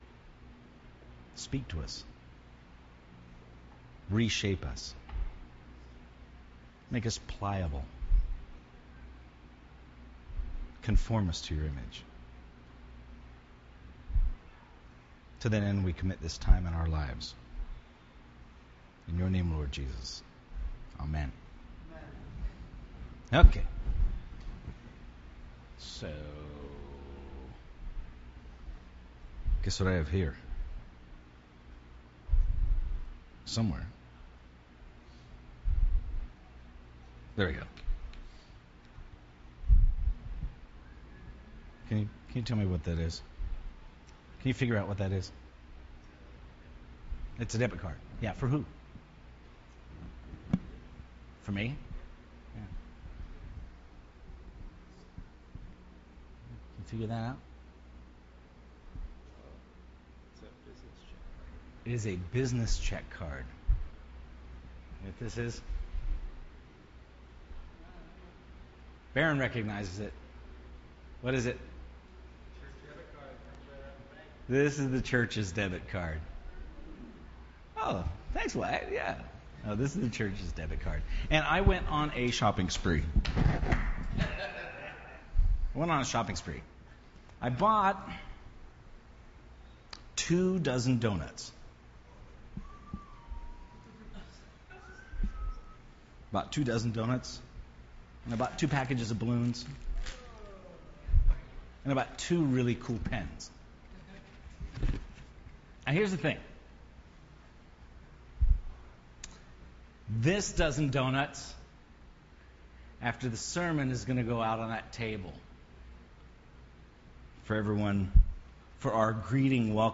The Core Service Type: Sunday Morning %todo_render% Related « The Core